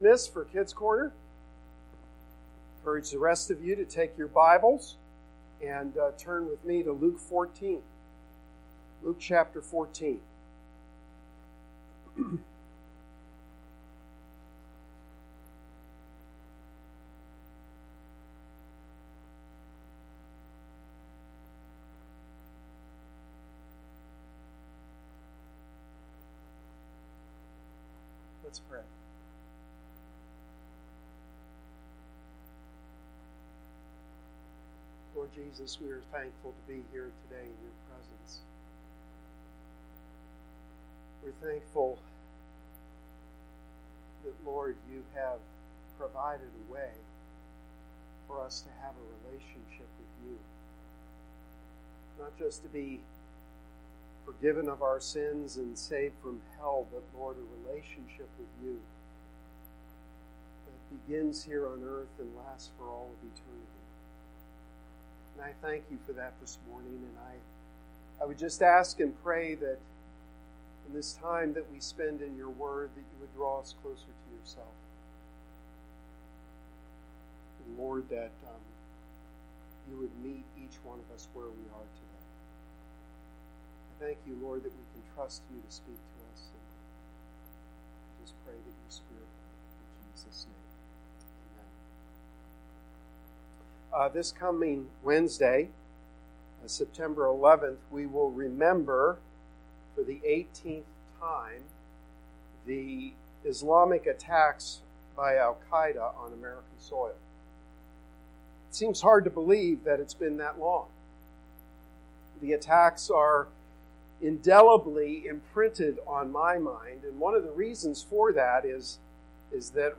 Sermon-9-8-19.mp3